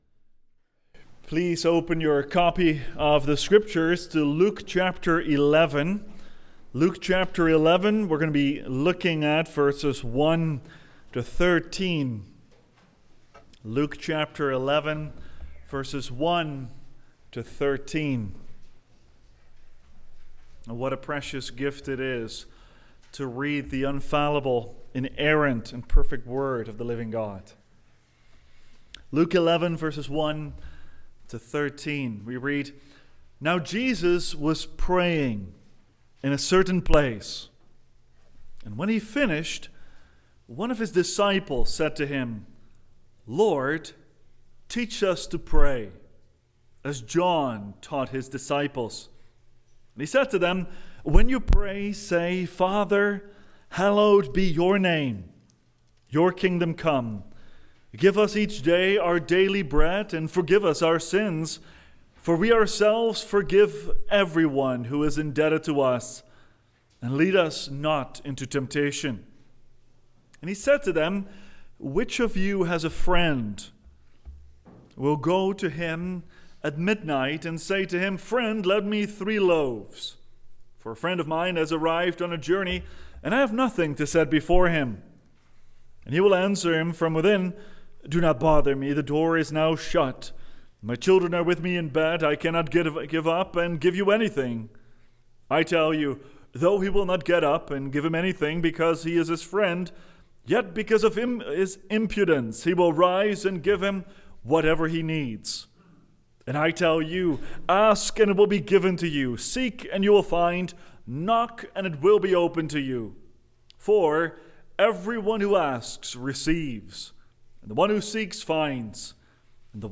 Service of Prayer for Crops and Industry.